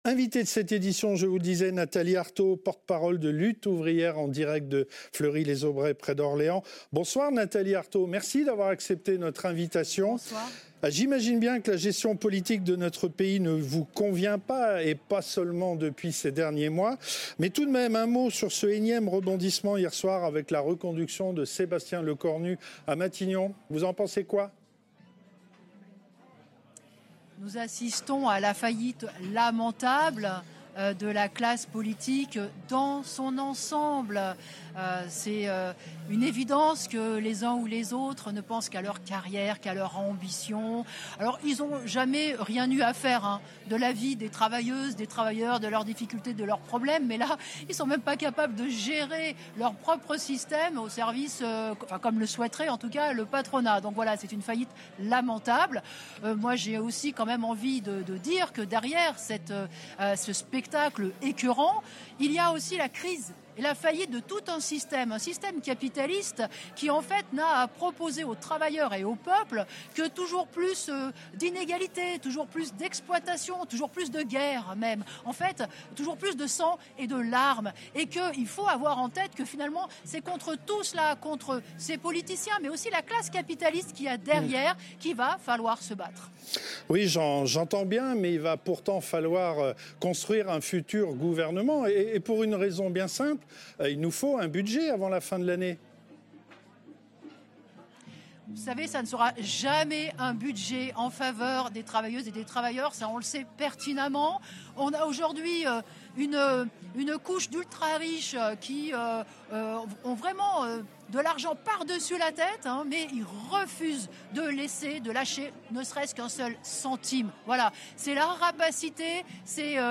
Fête à Orléans : Interview de Nathalie Arthaud